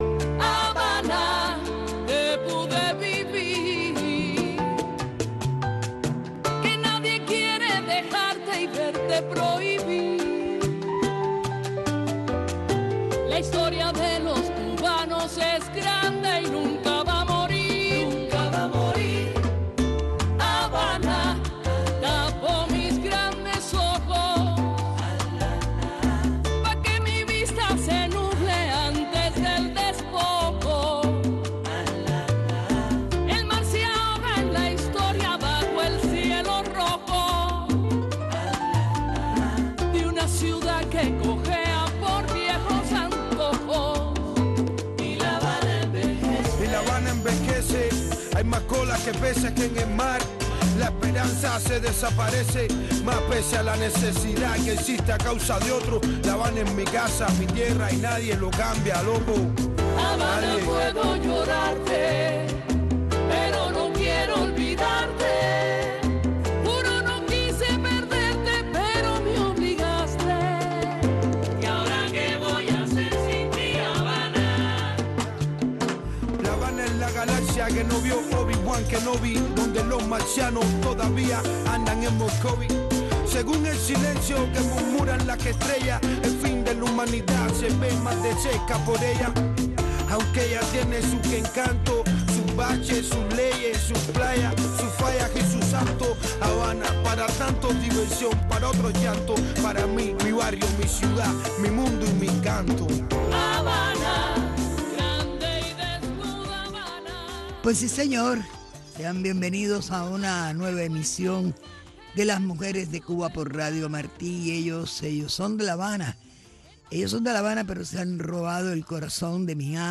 Un programa narrado en primera persona por las protagonistas de nuestra historia.